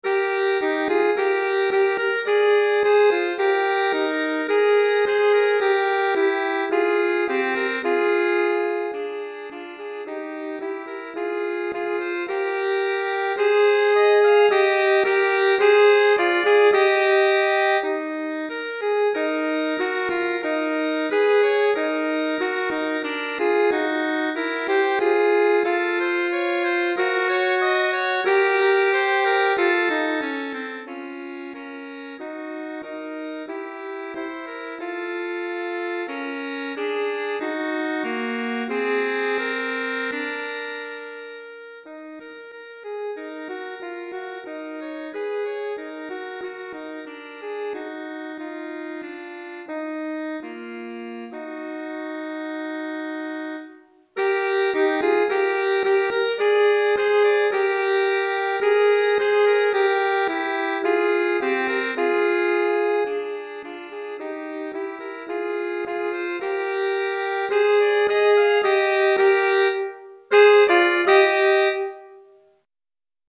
Pour 2 saxophones (possible 2 altos, 2 sopranos ou 2 ténors)